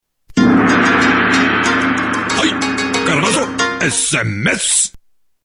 Japanese Sms